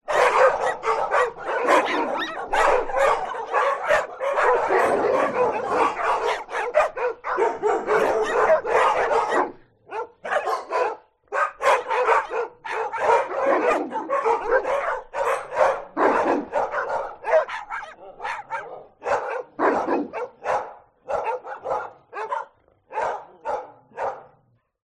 Громкий лай стаи собак